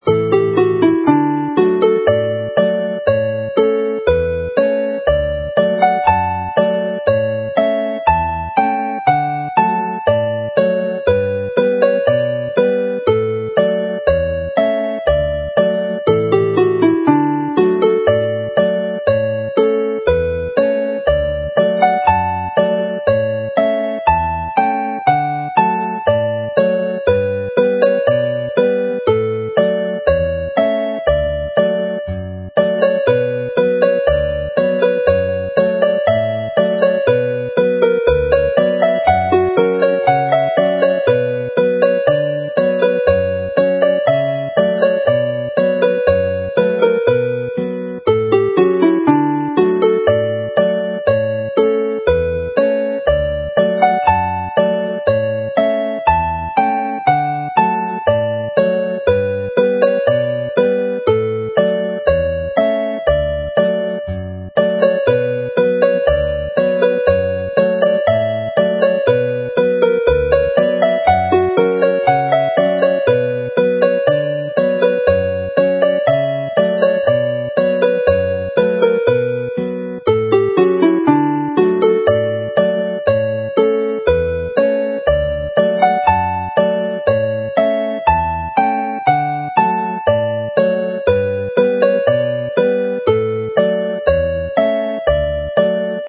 The set, like last month's, ends in a lively and jolly mood with Cainc y Datgeiniad (the singer's tune) which is commonly used as a base for Welsh Penillion singing.